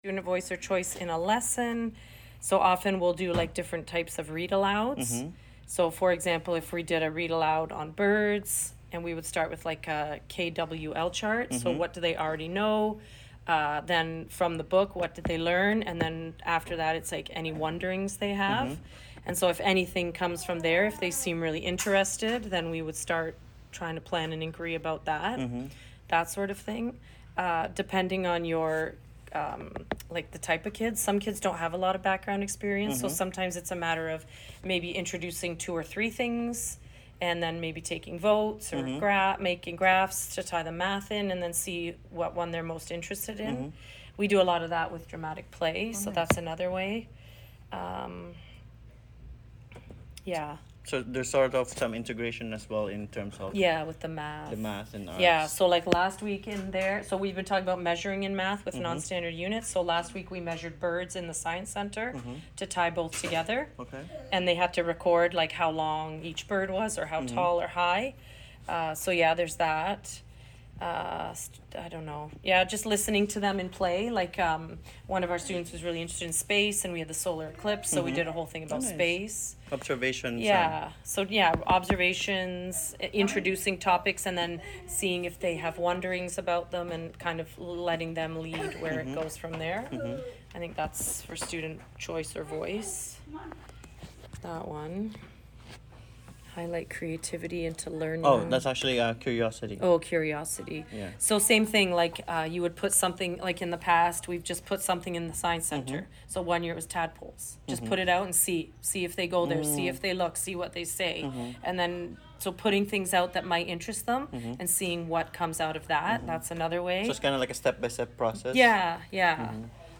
Interview with a teacher on inquiry for learning primary/junior level.
interview-with-a-teacher-inquiry-for-learning.mp3